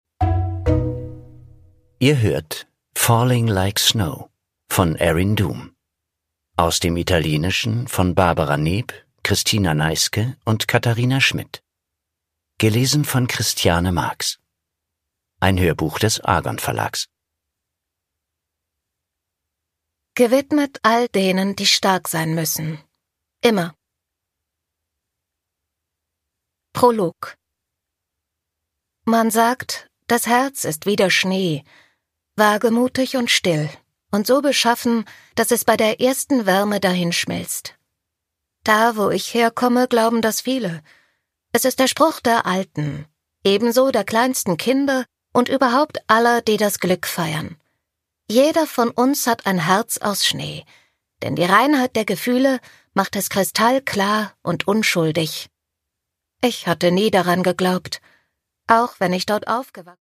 Produkttyp: Hörbuch-Download
Man spürt zu jeder Sekunde, dass sie diesen poetischen Roman und seine sinnliche Sprache liebt.